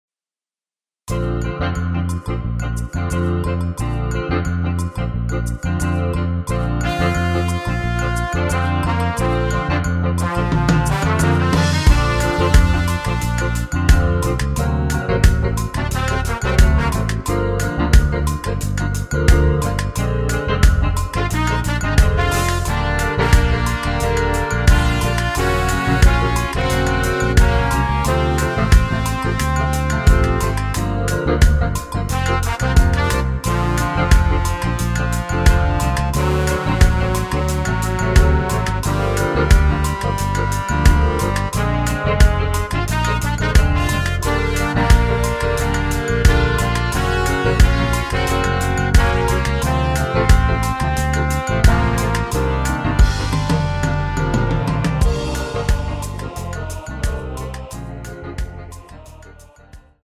원키에서(-6)내린 MR입니다.
엔딩이 길어 축가에 사용 하시기 좋게 엔딩을 짧게 편곡 하였습니다.(원키 코러스 버전 미리듣기 참조)
Bb
미리듣기는 저작권법상 최고 1분 까지라서
앞부분30초, 뒷부분30초씩 편집해서 올려 드리고 있습니다.